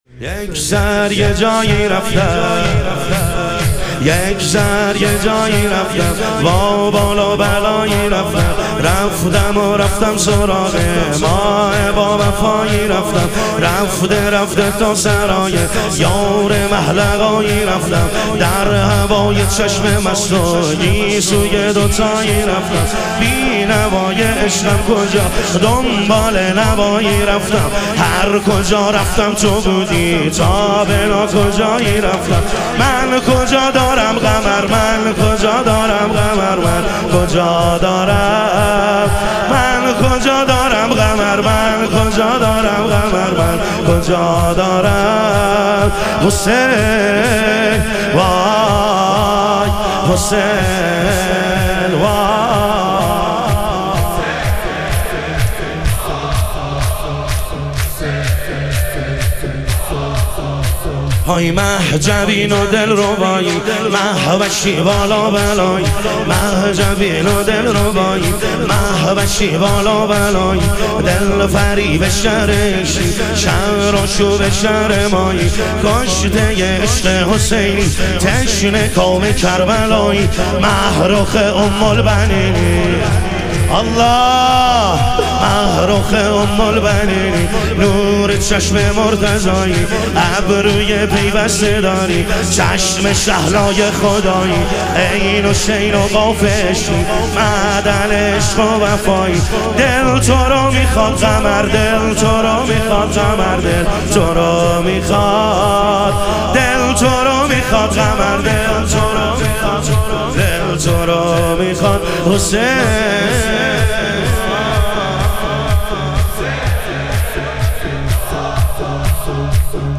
شور
شب شهادت حضرت ام البنین علیها سلام